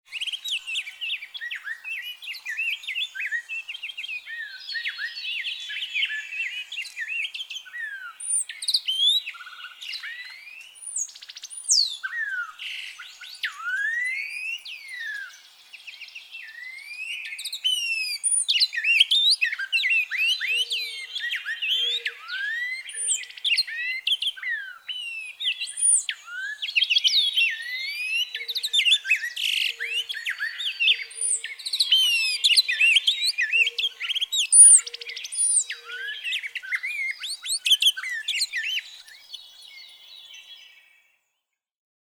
Rose-breasted grosbeak
Courtship song, far beyond spectacular, a singing serenade that certainly impresses any human listener.
Quabbin Park, Ware, Massachusetts.
099_Rose-breasted_Grosbeak.mp3